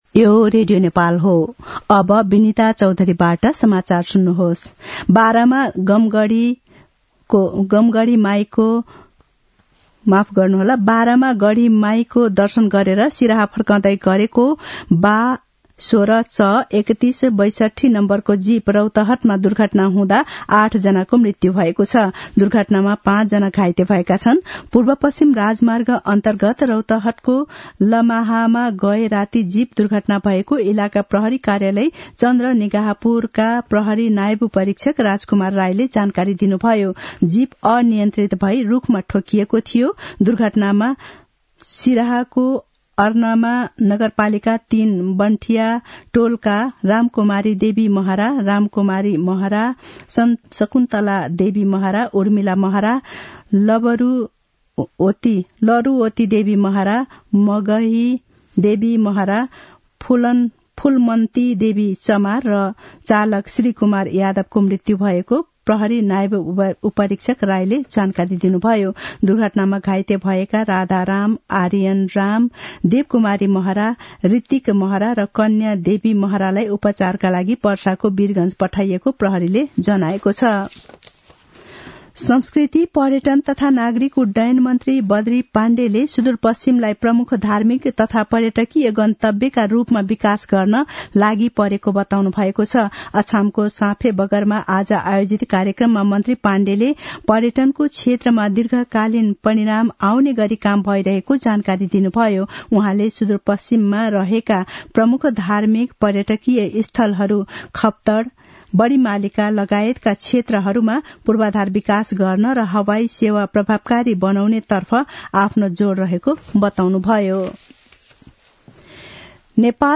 दिउँसो १ बजेको नेपाली समाचार : २६ मंसिर , २०८१
1-pm-nepali-news-1-8.mp3